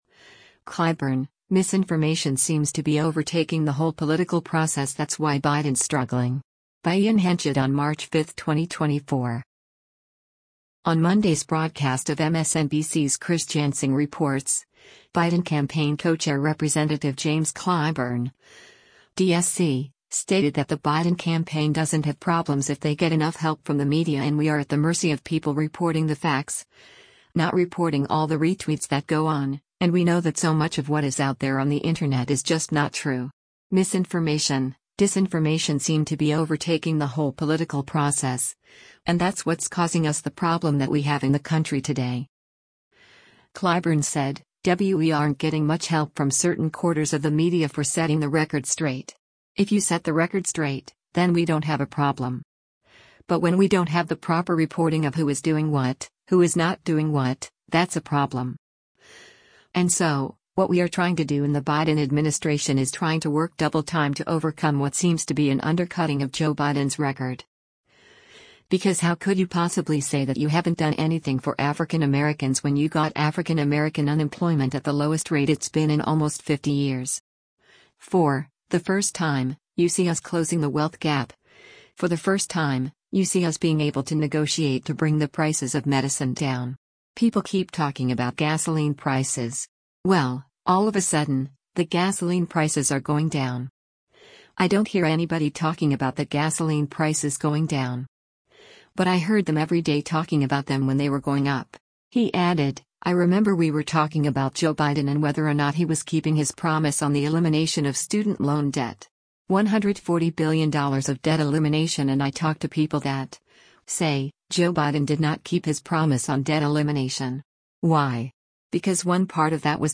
On Monday’s broadcast of MSNBC’s “Chris Jansing Reports,” Biden Campaign Co-Chair Rep. James Clyburn (D-SC) stated that the Biden campaign doesn’t have problems if they get enough help from the media and “we are at the mercy of people reporting the facts, not reporting all the retweets that go on, and we know that so much of what is out there on the Internet is just not true.